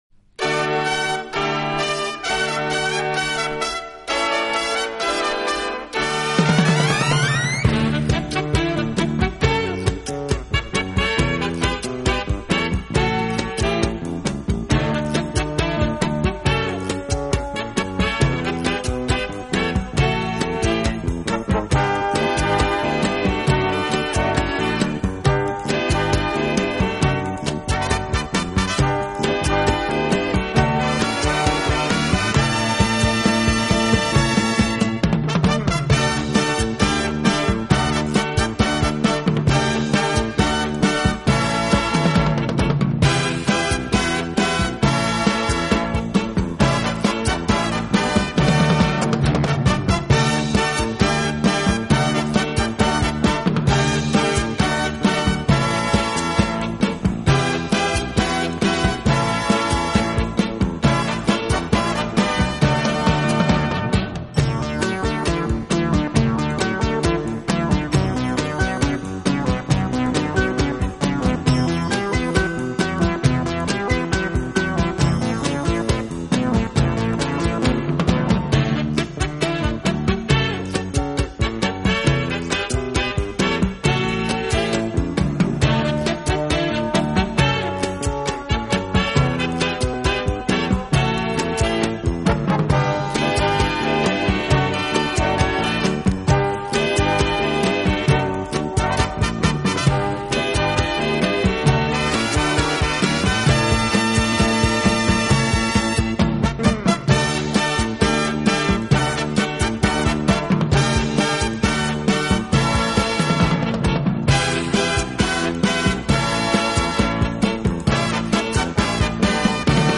【黑管专辑】
Cha-Cha 33 T/M